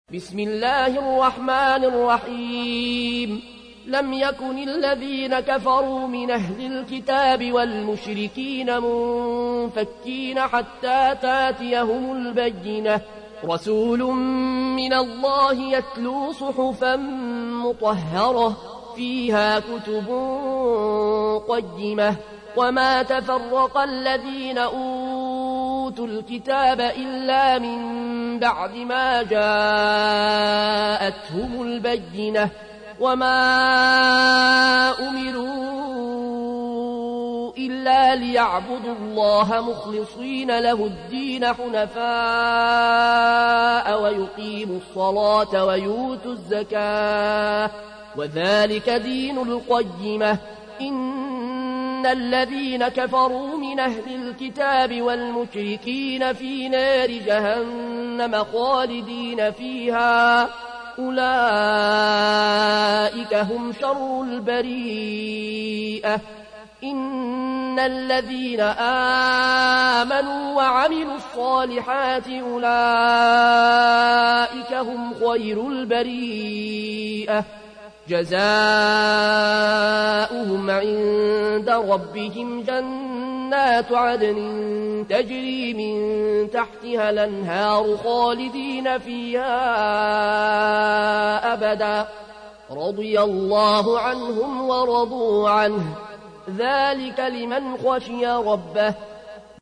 تحميل : 98. سورة البينة / القارئ العيون الكوشي / القرآن الكريم / موقع يا حسين